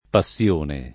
[ pa SSL1 ne ]